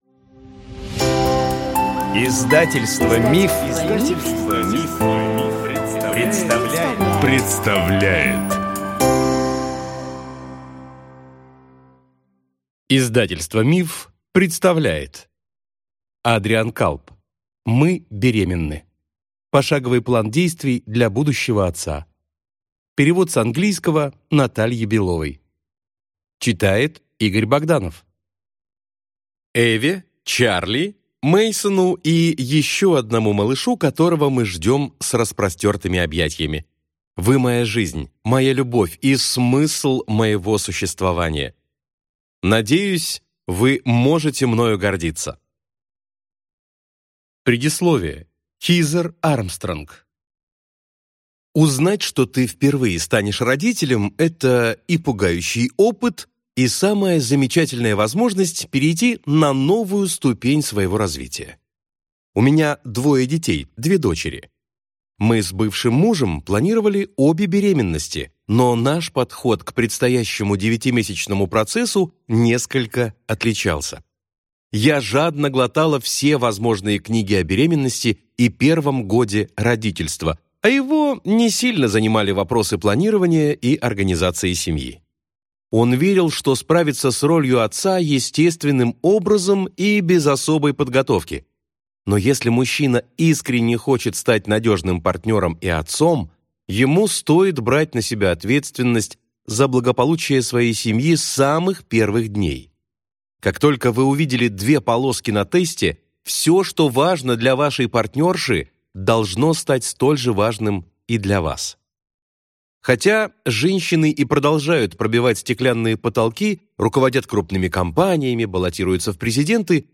Аудиокнига Мы беременны! Пошаговый план действий для будущего отца | Библиотека аудиокниг